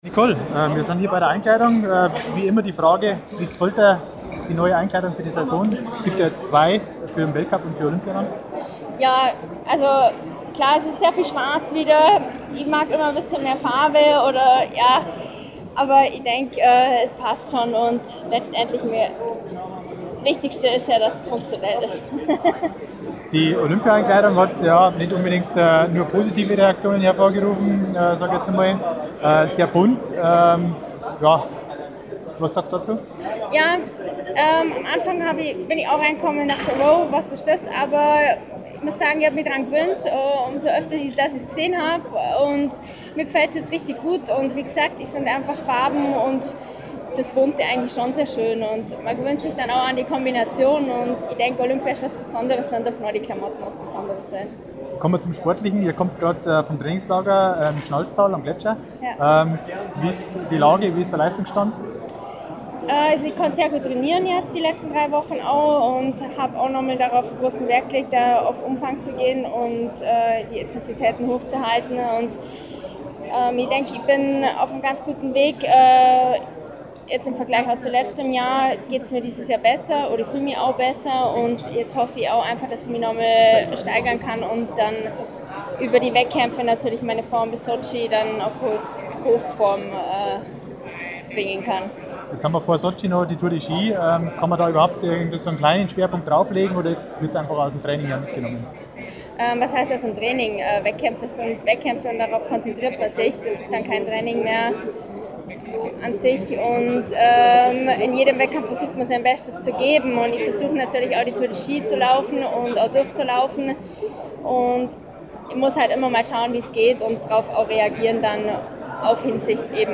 Im Interview am Rande der DSV-Einkleidung in Eching spricht Nicole Fessel über die neuen Klamotten, ihre Vorbereitung und und die Rennen bei den Olympischen Spielen in Sochi.
audio-interview-mit-nicole-fessel-einkleidung-2013.mp3